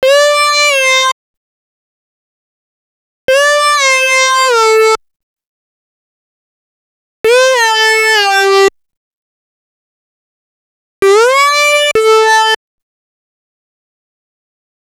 Session 11 - Lead 02.wav